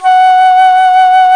Index of /90_sSampleCDs/Roland LCDP04 Orchestral Winds/FLT_Alto Flute/FLT_A.Flt vib 2
FLT ALTOFL0J.wav